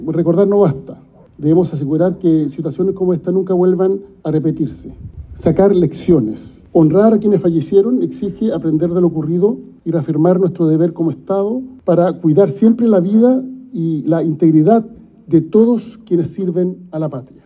Por su parte, el ministro del Interior, Álvaro Elizalde, enfatizó en que nunca más debe ocurrir un hecho de estas características en nuestro país.